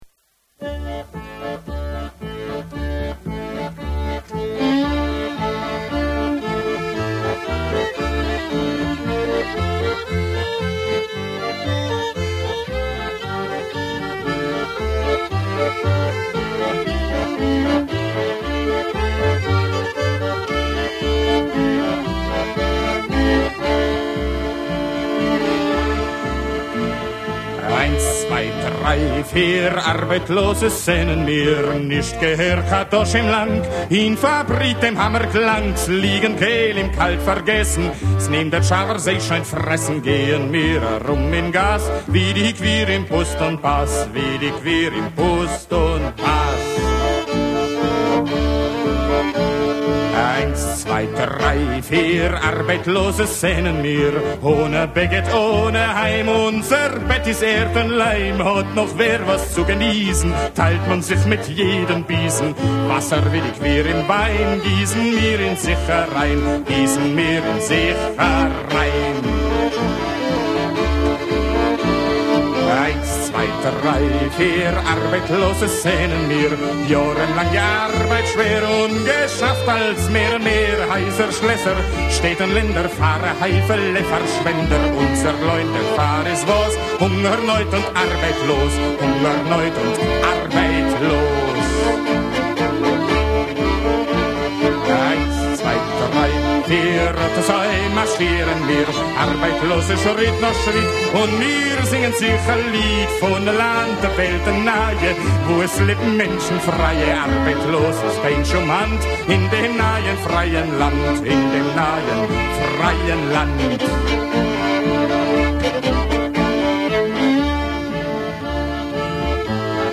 PJESMA SREDNJOEVROPSKIH ŽIDOVA SOCIJALISTA NA JIDIŠU